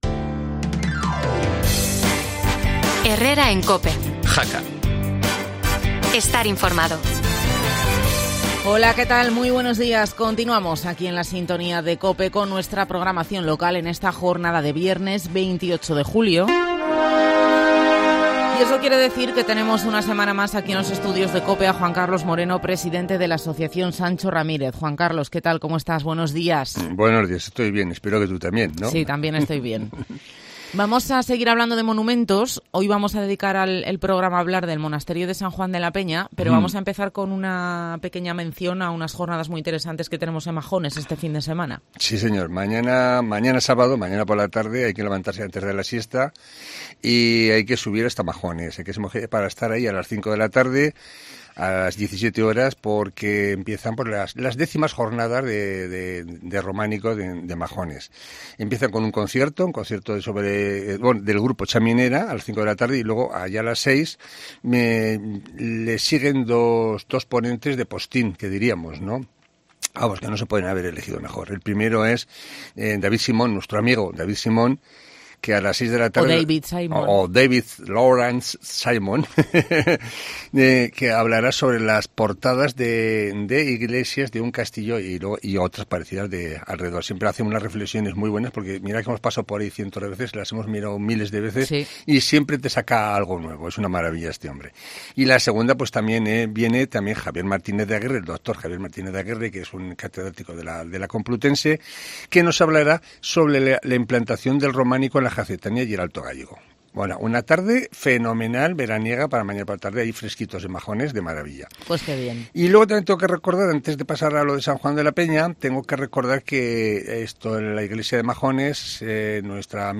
No te pierdas la entrevista en COPE